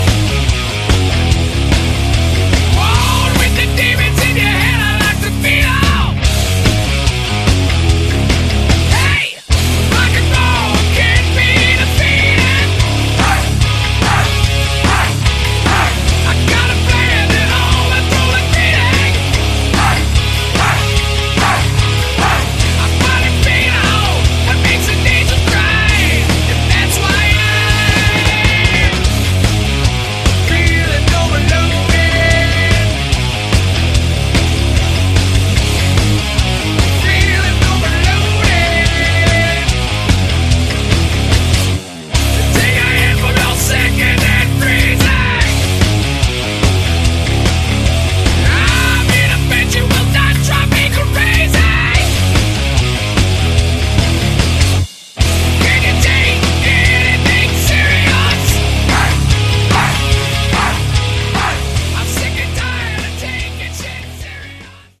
Category: sleazy hard rock
Vocals
Guitar
Bass
Drums